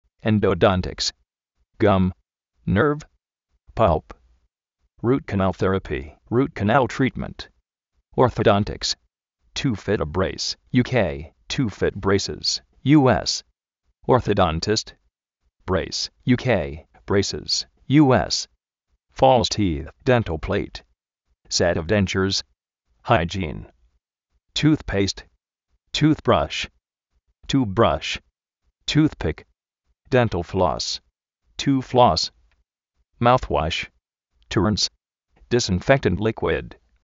endo-dóntiks
orzo-dóntiks
jaiyín
máuz uósh